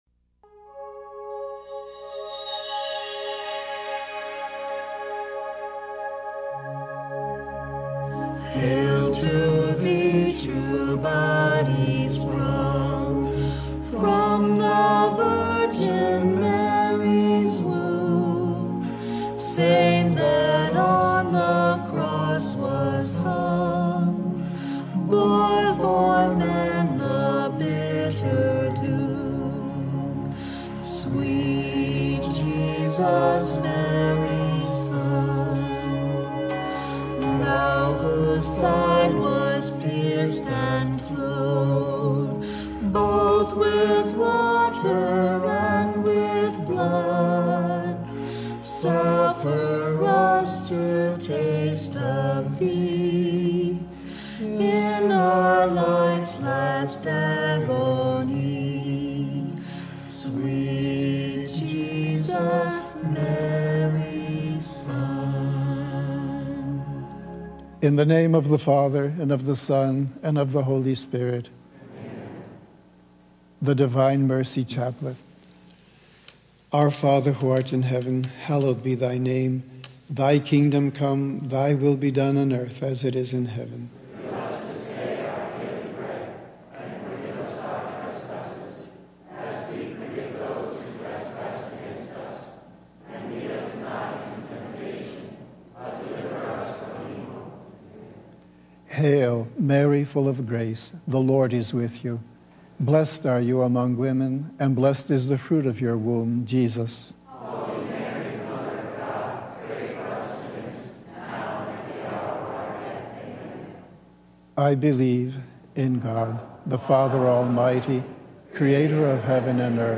The Chaplet of Divine Mercy in Song (complete).mp3